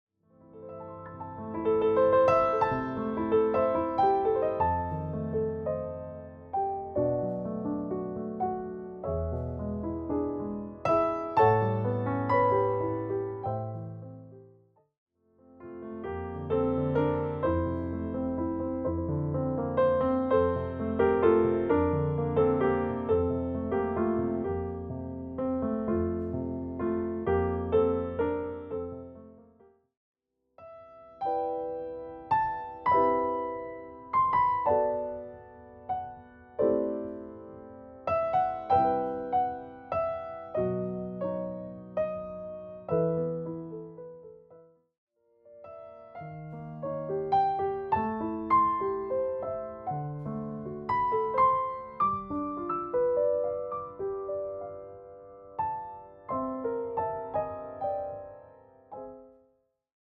With its steady, expressive piano style